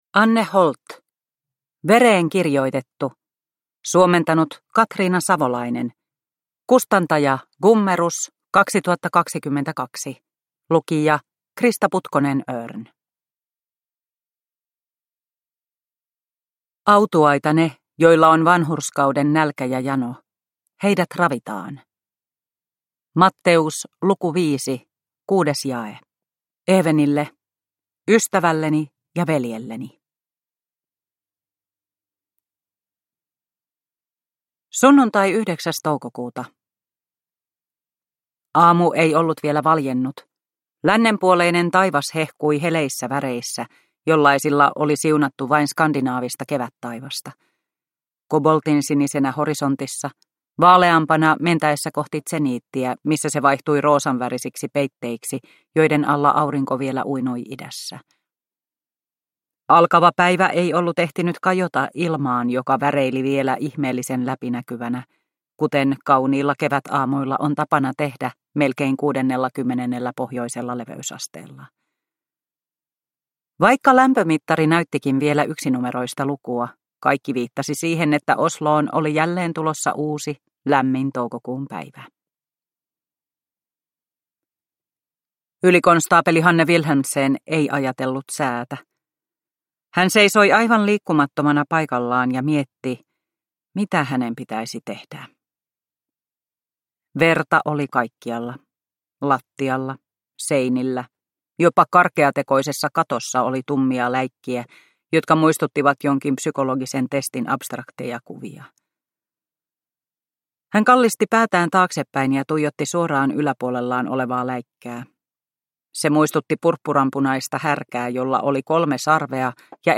Vereen kirjoitettu – Ljudbok – Laddas ner